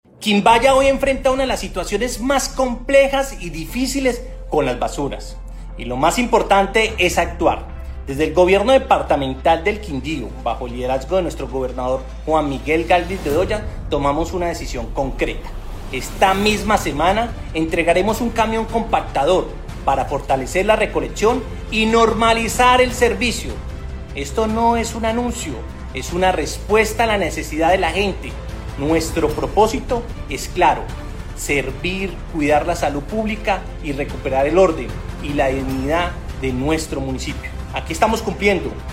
Jaime Andrés Pérez, secretario interior del Quindío